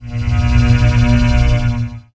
blinksuccess.wav